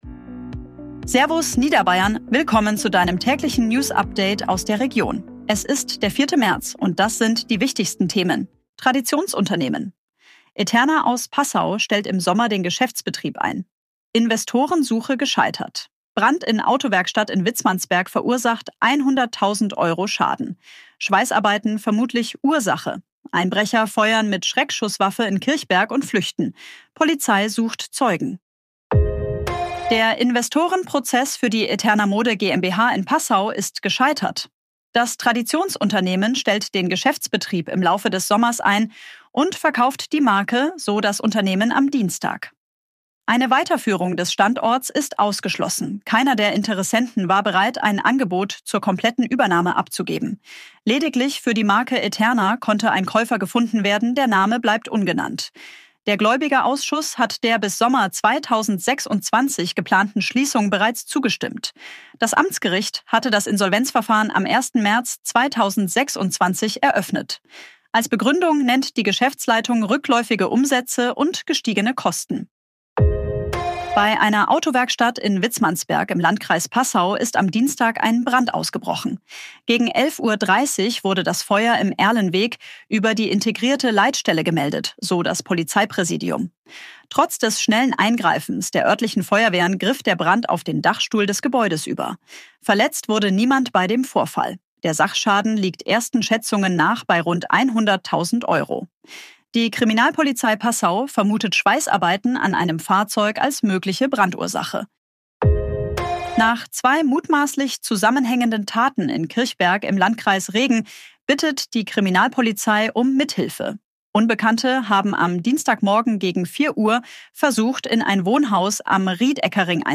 Tägliche Nachrichten aus deiner Region
künstlicher Intelligenz auf Basis von redaktionellen Texten